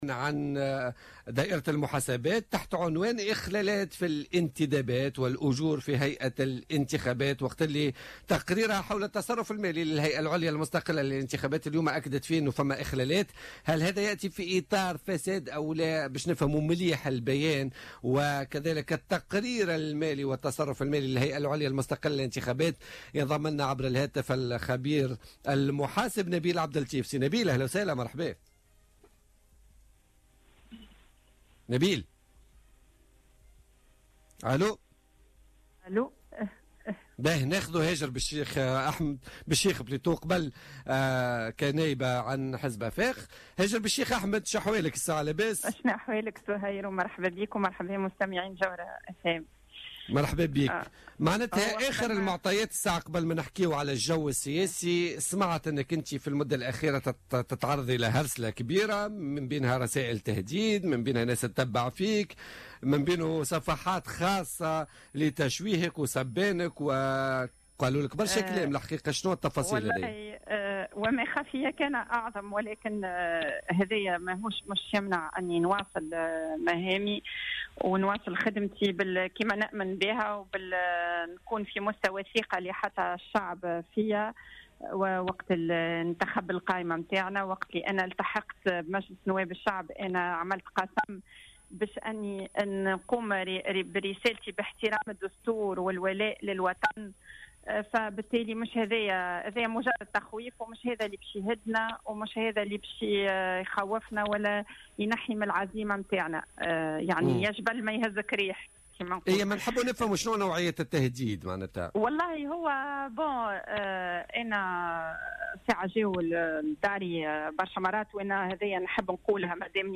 أكدت النائبة عن حزب افاق تونس هاجر بالشيخ أحمد في مداخلة لها في بولتيكا اليوم الخميس أنها تتعرض منذ فترة لعمليات هرسلة وتهديدات متواصلة بسبب مواقفها داخل المجلس.